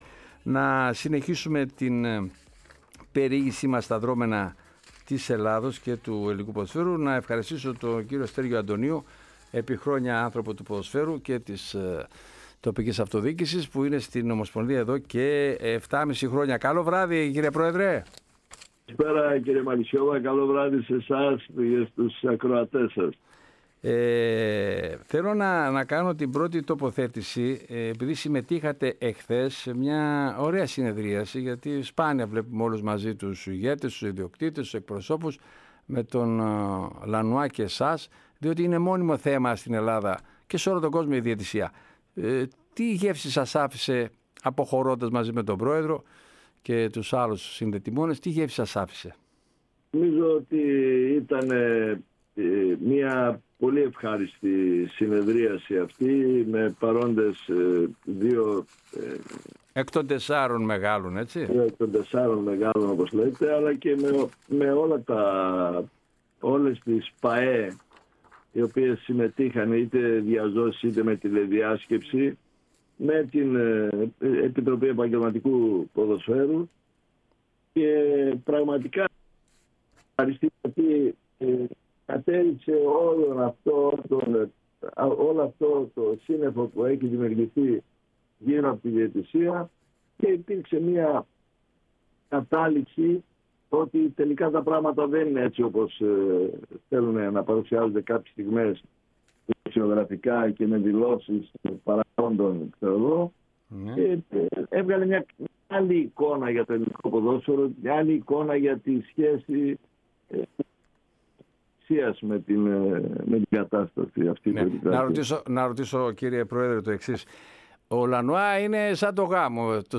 φιλοξενήθηκε στην εκπομπή της ΕΡΑ ΣΠΟΡ «Τελευταία Σελίδα»